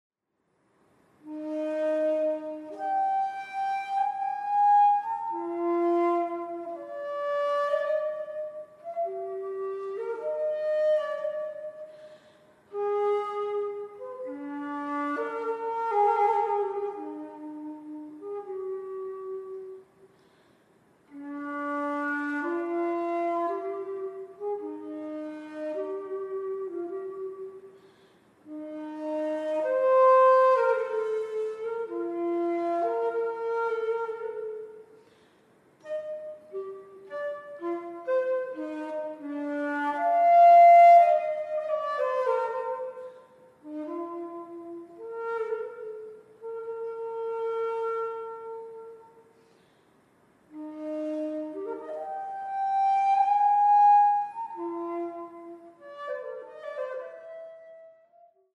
baroque flute